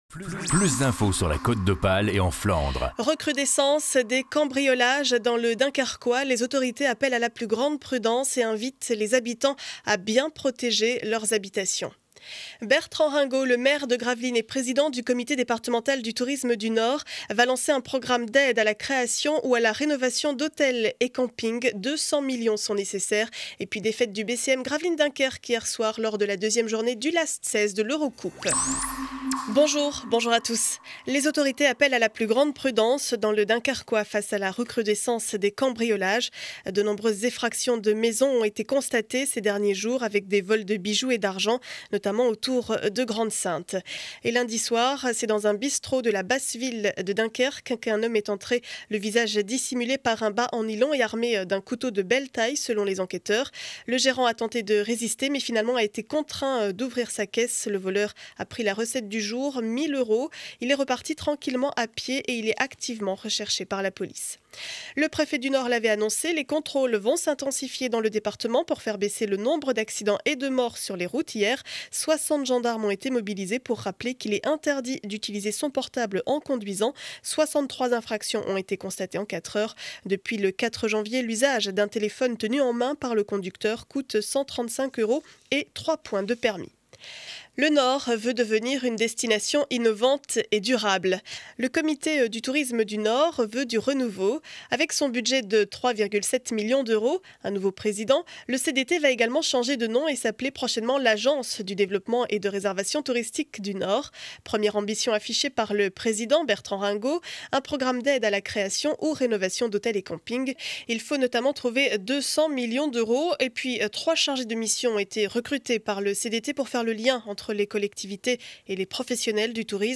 Journal du mercredi 25 janvier 2012 7 heures 30 édition du Dunkerquois.